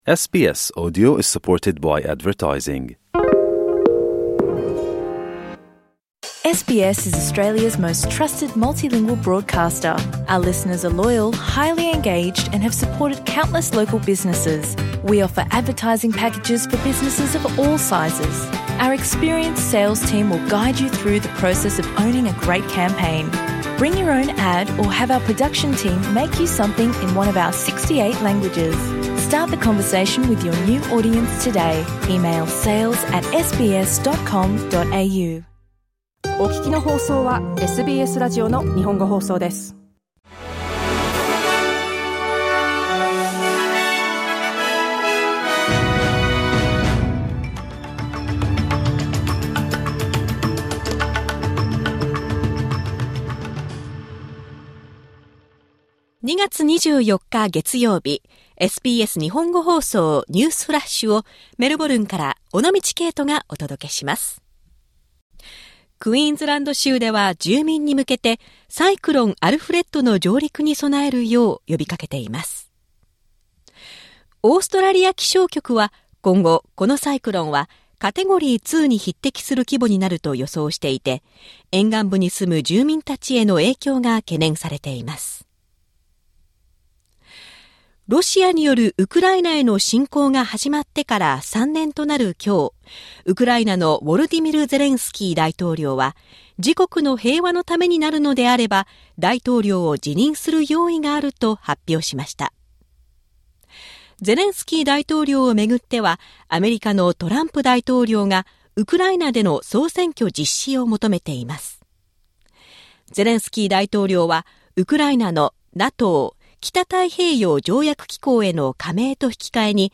SBS Japanese Newsflash Monday 24 February